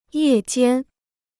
夜间 (yè jiān) Free Chinese Dictionary